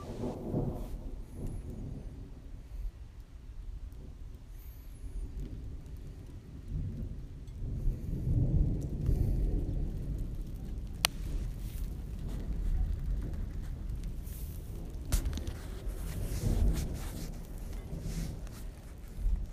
I wanted to wait and look for more photos, but I’ll do this today: yesterday’s storm.
thunder2.m4a